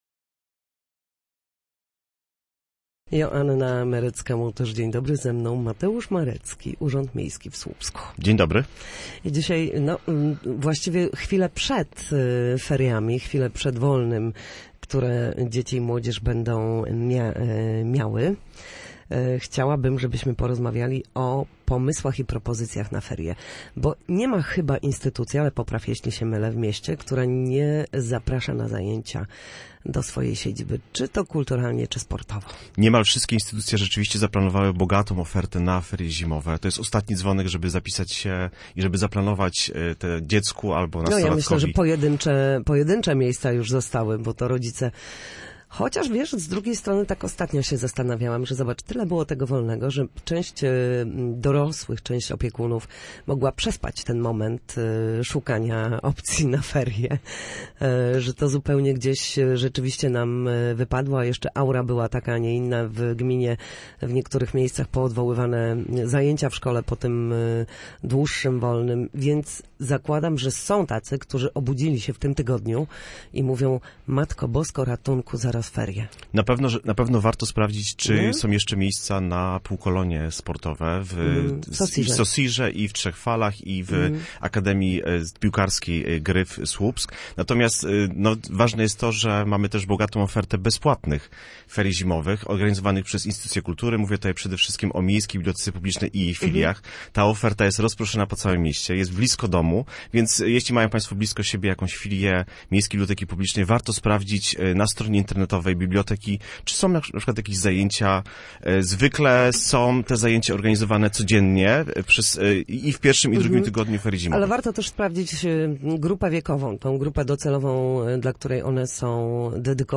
Ferie w mieście nie muszą być nudne. O propozycjach dla młodych Słupszczan mówił w Studiu Słupsk